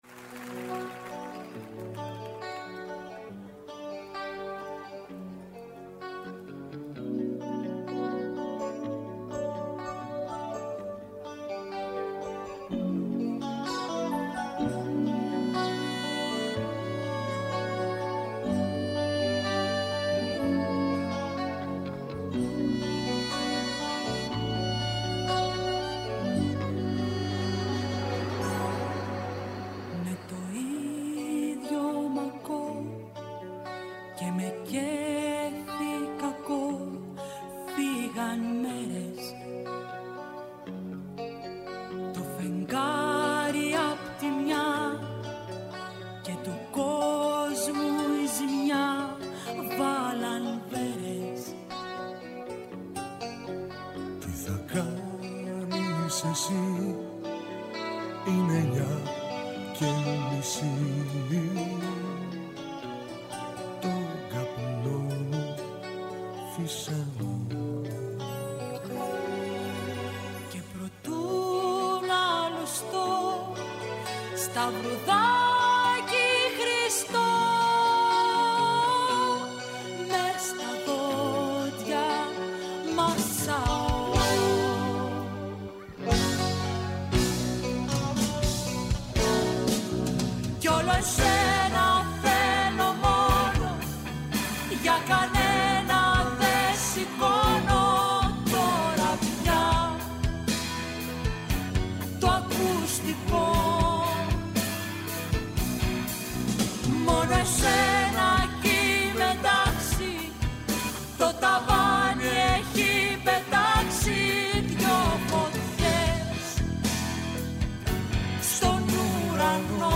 Στην εκπομπή φιλοξενήθηκε από το Ελσίνκι